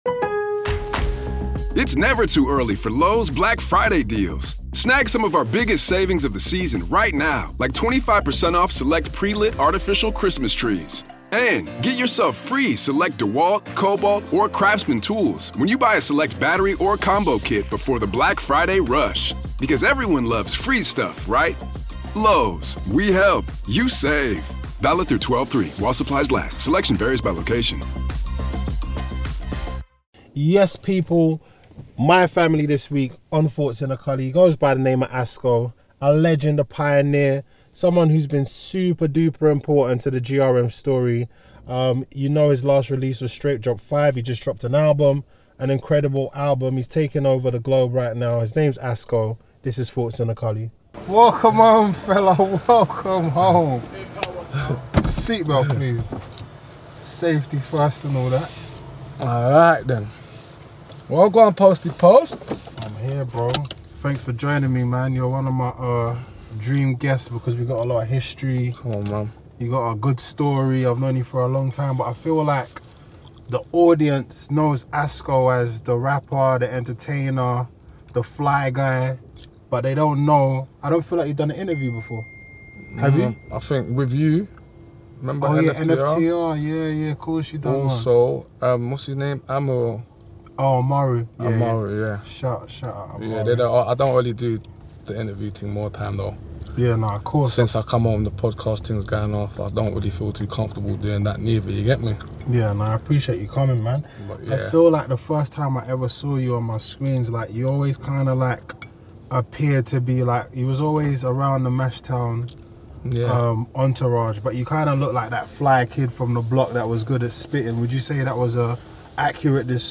have a shared history, and that makes for great conversation from the two.